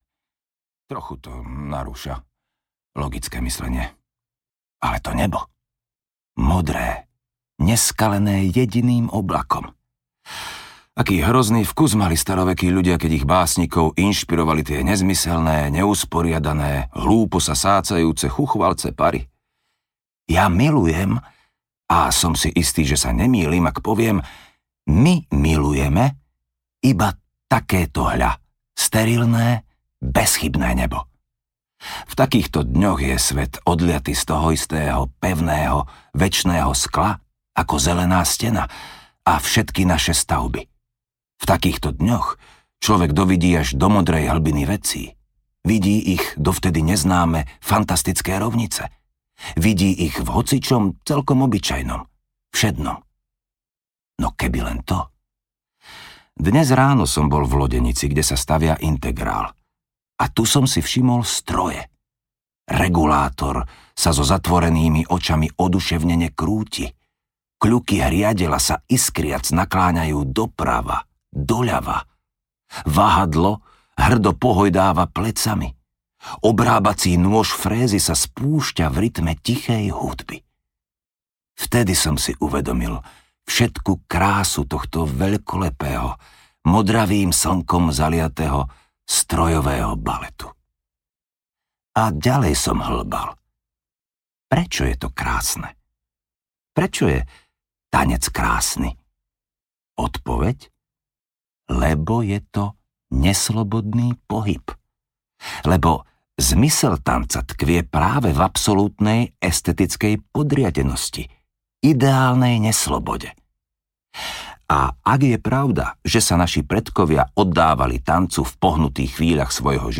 My audiokniha
Ukázka z knihy